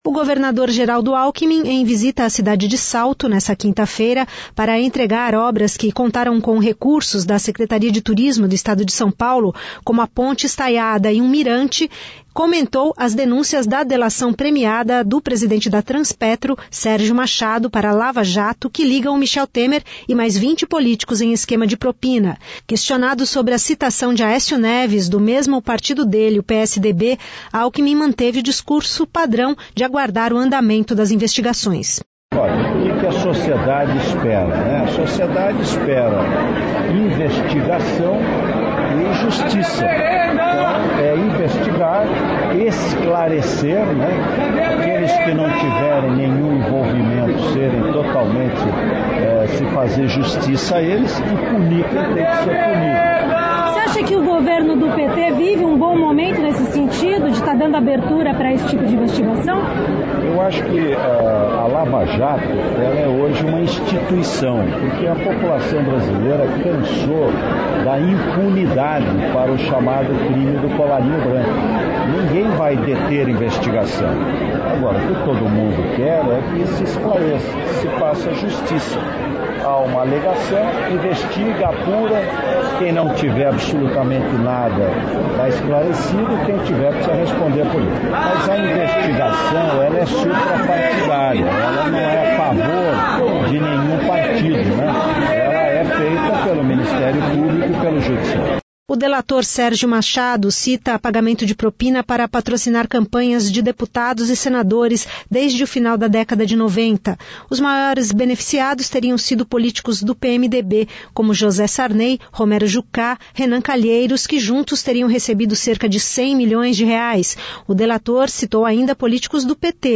Durante a entrevista coletiva, uma pessoa não identificada aproveitou os microfones abertos para protestar sobre  um suposto  superfaturamento e pagamento de propina em contratos de fornecimento de merenda escolar pelo Governo de São Paulo, que está sendo investigado pelo Ministério Público.